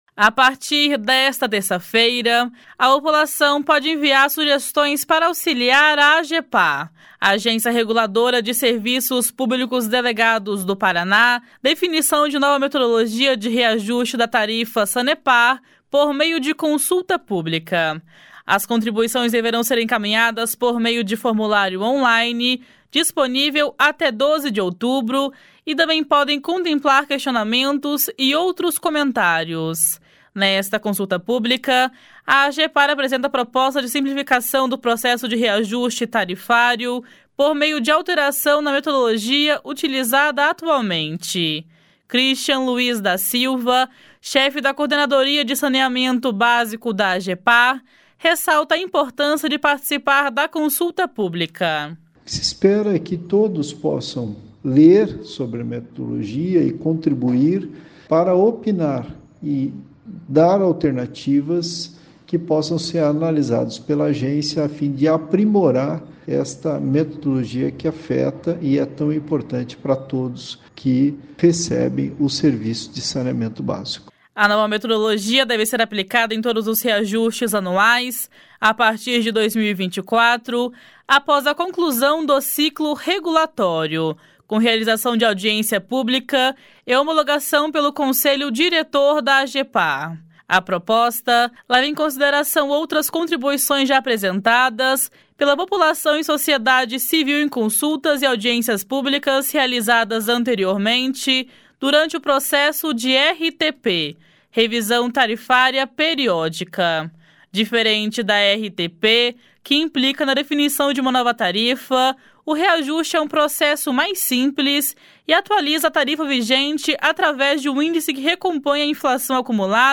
(Repórter